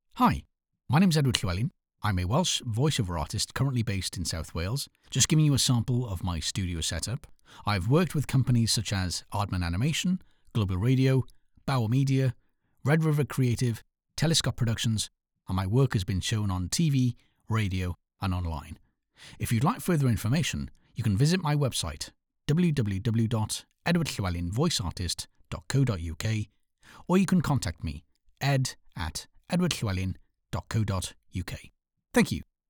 Southern Welsh, Northern Welsh, RP, Liverpool,
Middle Aged
HOME STUDIO SAMPLE.mp3